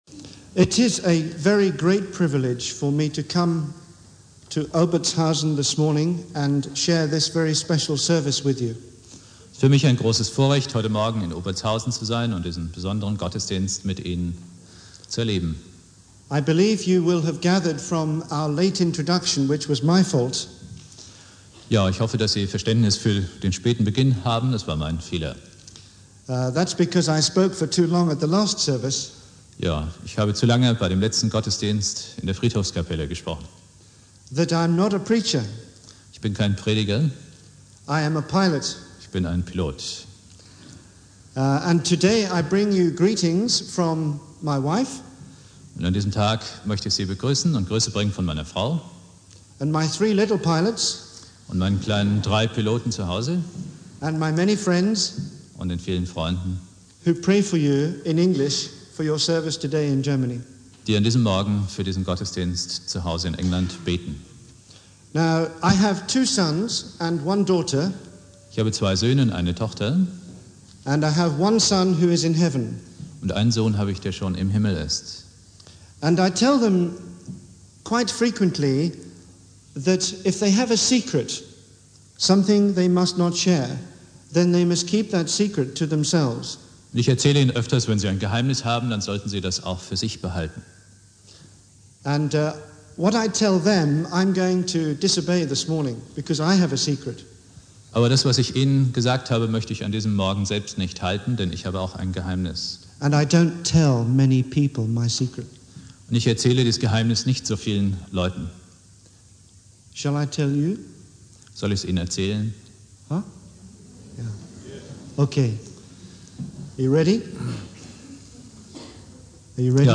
Predigt
Thema: Die Auferweckung des Lazarus (Einführung der neuen Konfirmanden aus Obertshausen)